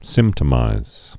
(sĭmtə-mīz, sĭmp-) or symp·tom·a·tize (-tə-mə-tīz)